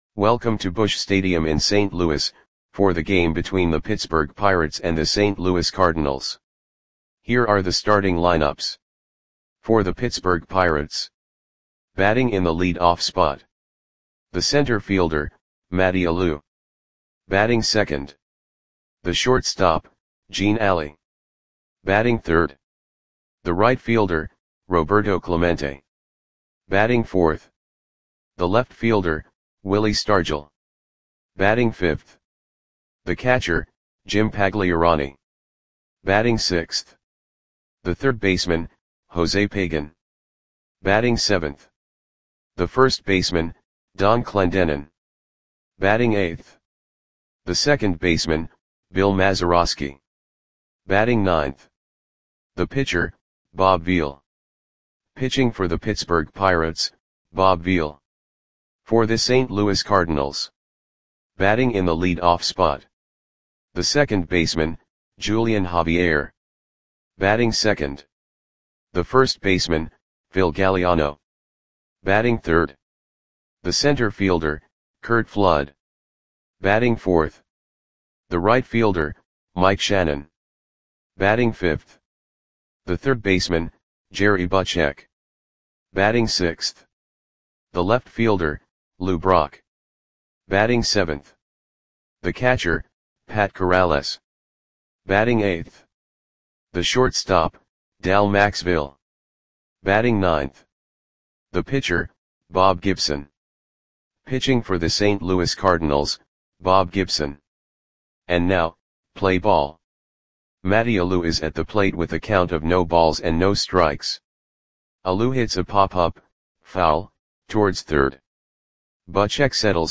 Lineups for the St. Louis Cardinals versus Pittsburgh Pirates baseball game on June 15, 1966 at Busch Stadium (St. Louis, MO).
Click the button below to listen to the audio play-by-play.